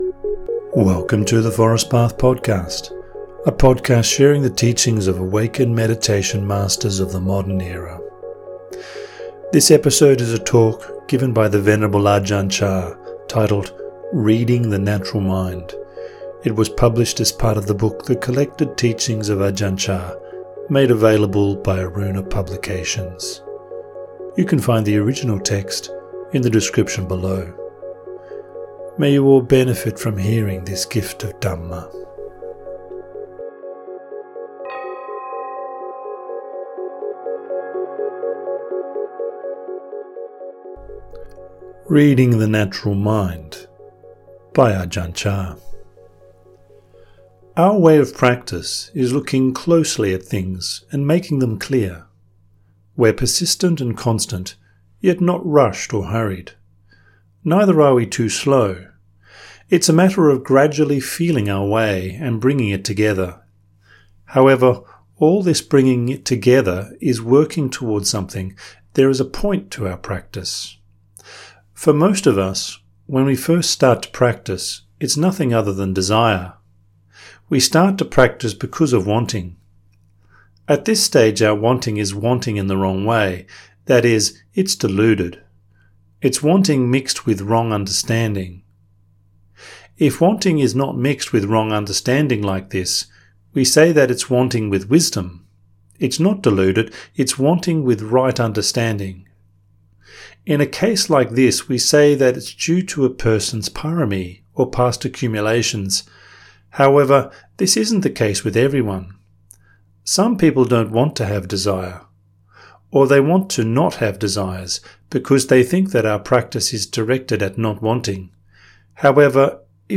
Narrated translations of meditation masters of the forest tradition of Theravada Buddhism.